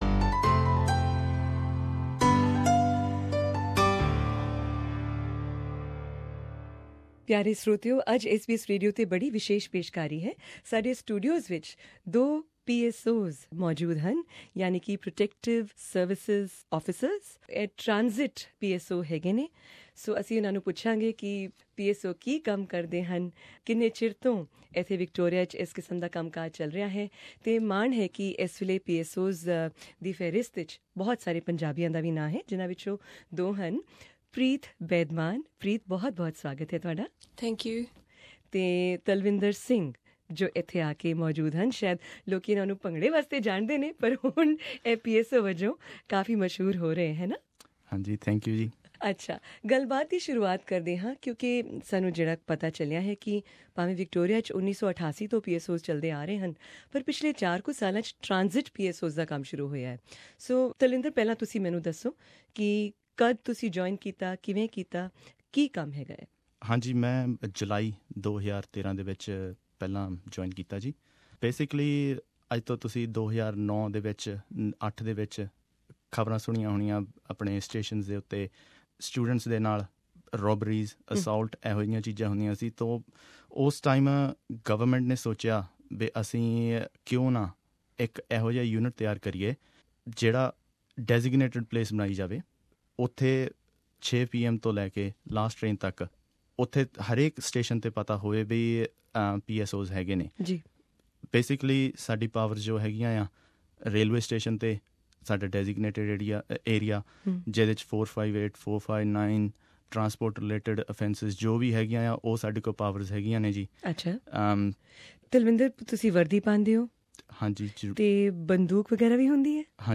Our special interview with two Punjabi PSOs of Victoria